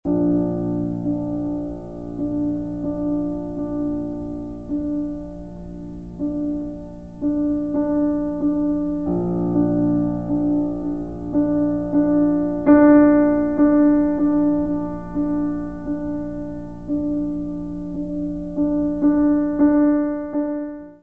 piano
Área:  Música Clássica